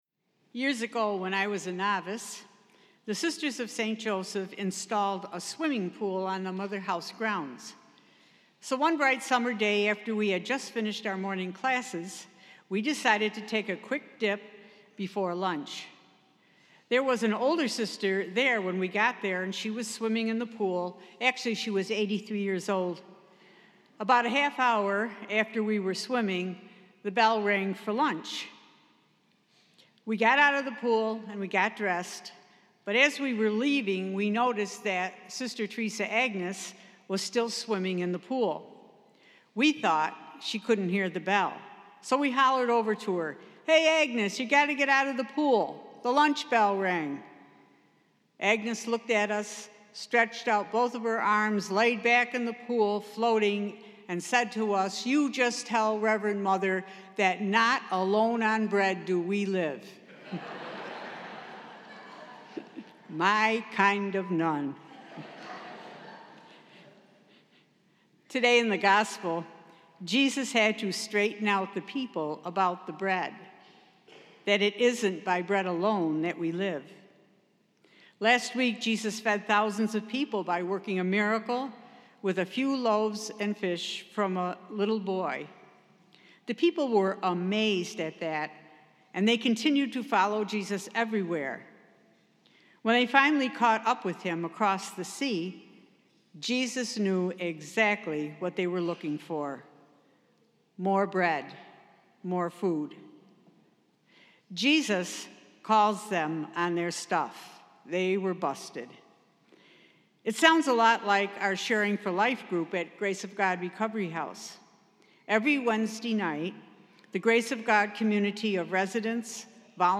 Spiritus Christi Mass August 5th, 2018